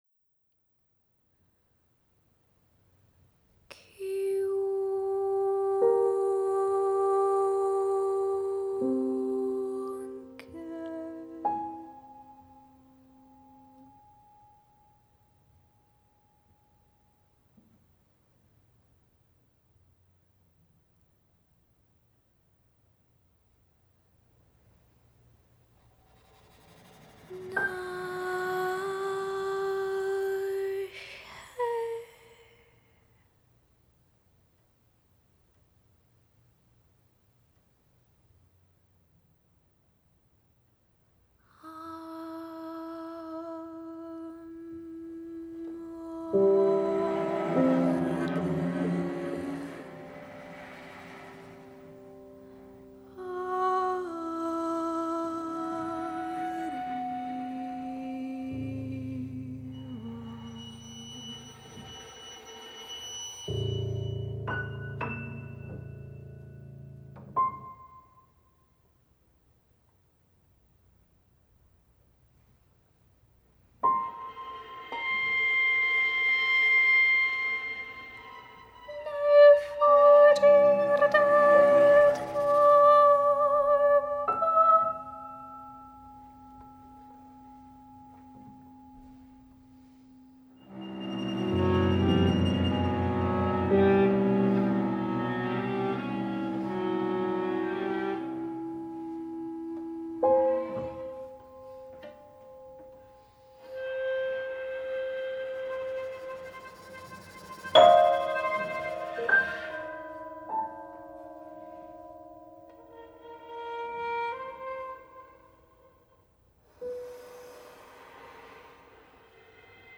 molto delicata e piena di silenzi.
per voce, piano, chimes, violino, viola, cello.